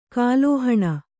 ♪ kālōhaṇa